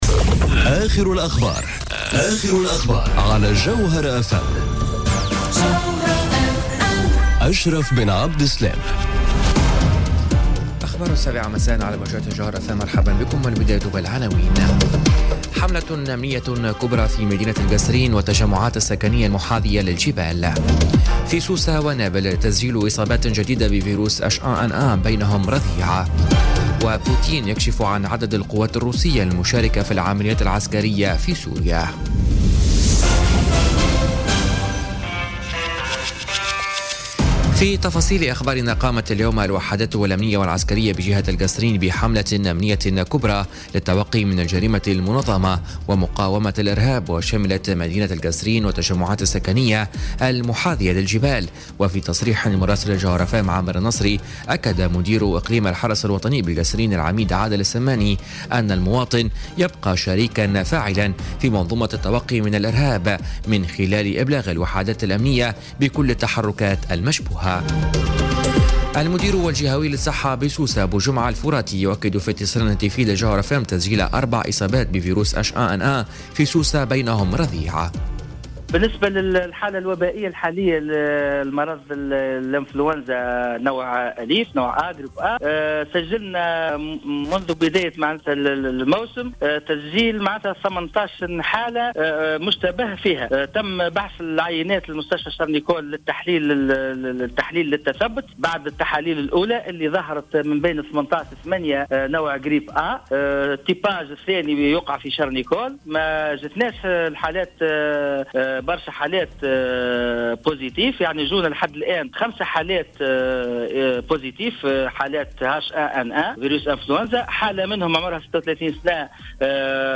نشرة أخبار السابعة مساءً ليوم الخميس 28 ديسمبر 2017